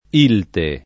vai all'elenco alfabetico delle voci ingrandisci il carattere 100% rimpicciolisci il carattere stampa invia tramite posta elettronica codividi su Facebook ILTE [ & lte ] n. pr. f. — sigla di Industria Libraria Tipografica Editrice